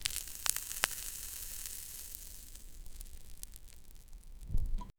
Royalty-free foleys sound effects
tiny-rustles-soft-crunch--t325l3ij.wav